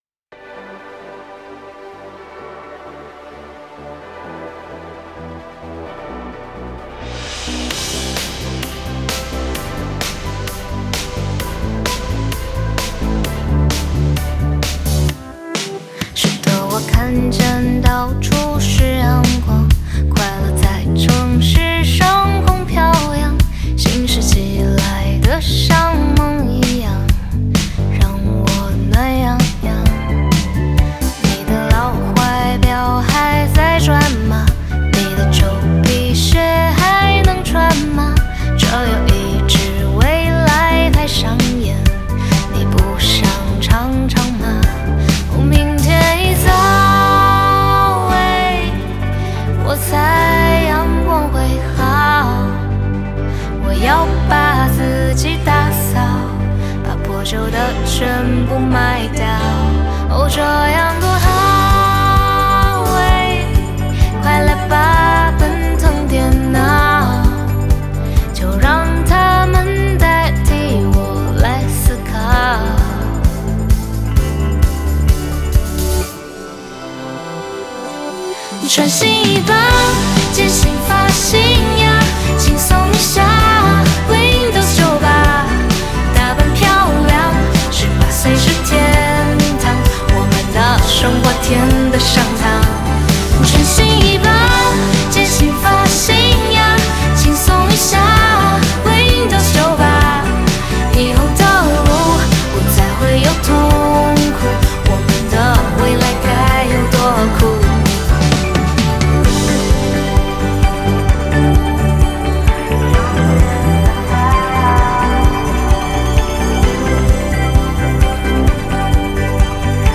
Ps：在线试听为压缩音质节选，体验无损音质请下载完整版
鼓/打击乐
吉他
合声
「此版本为正式授权翻唱作品」